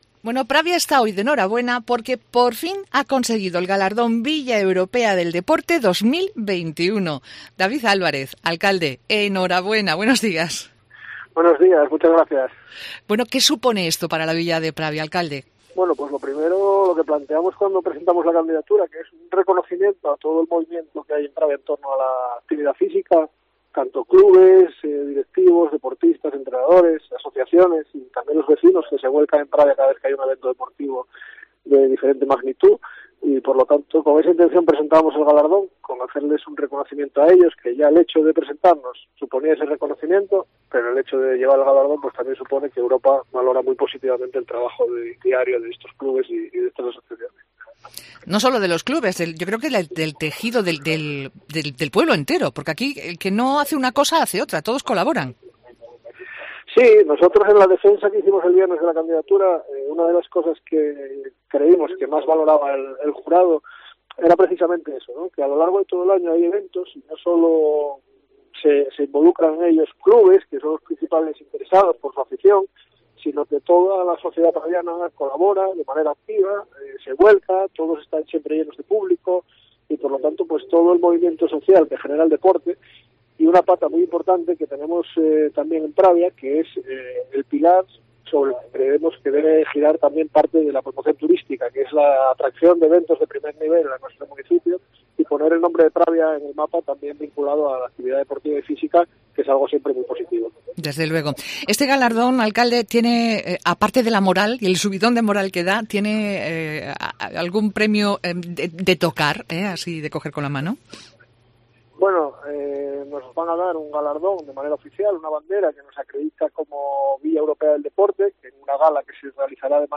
Entrevista a David Álvarez, alcalde de Pravia